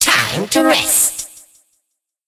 evil_mortis_kill_vo_03.ogg